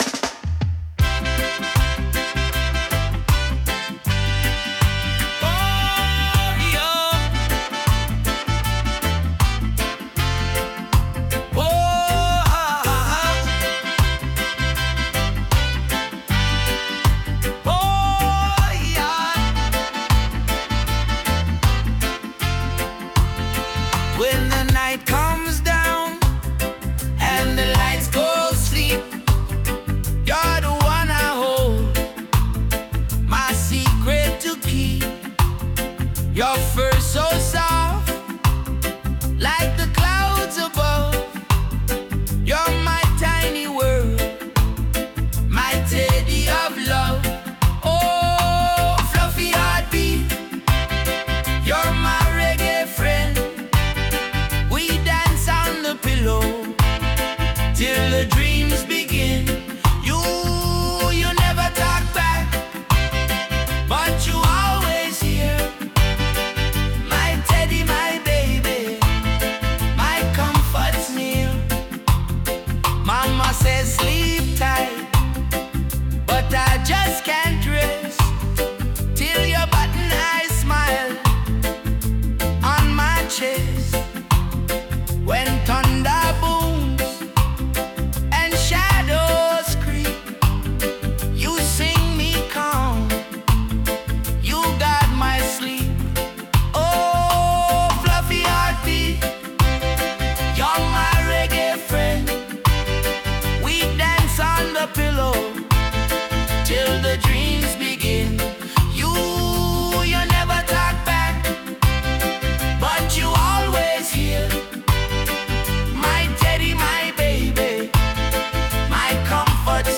Die Melodie im Reggae-Stil verleiht dem Ganzen eine sanfte, wiegende Leichtigkeit, fast wie das Schaukeln im Arm eines Elternteils.